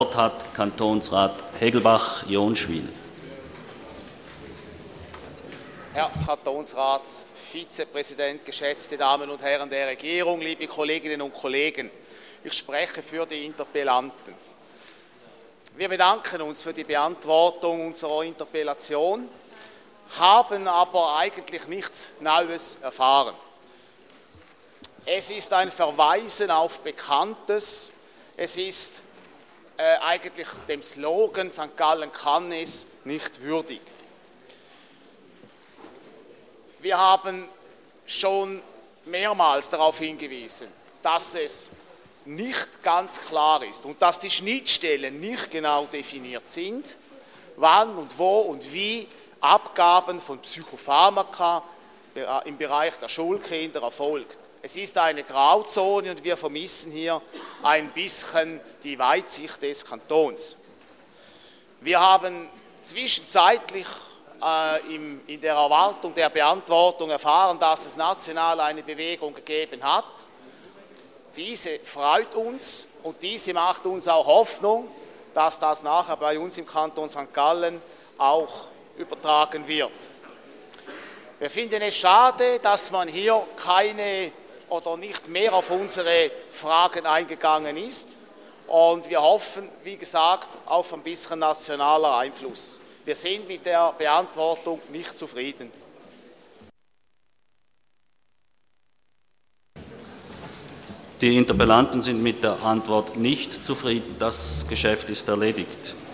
26.2.2013Wortmeldung
Session des Kantonsrates vom 25. bis 27. Februar 2013